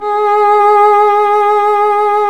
Index of /90_sSampleCDs/Roland - String Master Series/STR_Violin 1-3vb/STR_Vln1 % marc